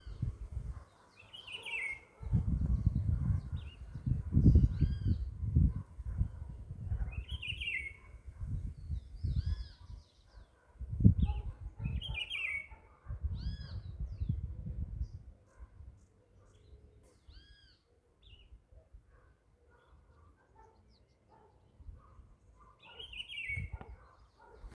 Rufous-browed Peppershrike (Cyclarhis gujanensis)
Condition: Wild
Certainty: Observed, Recorded vocal
Juan-chiviro.mp3